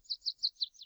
Birds 4.wav